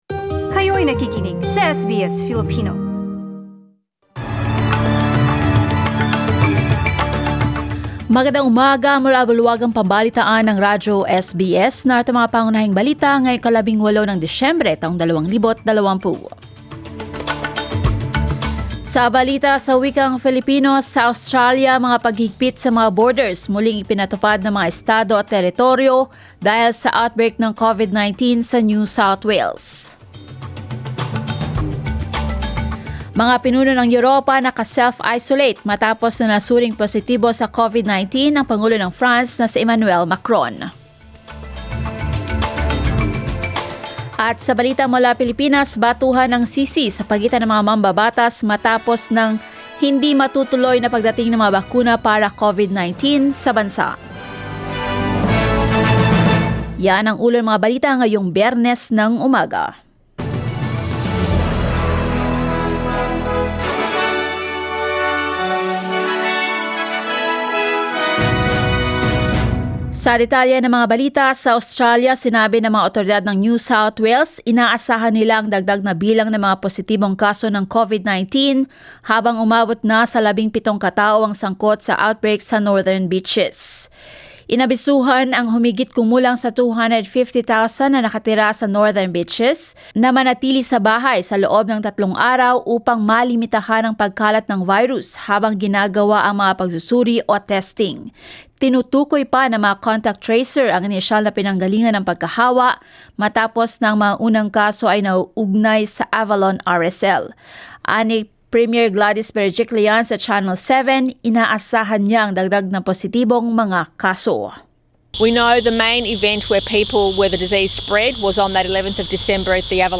SBS News in Filipino, Friday 18 December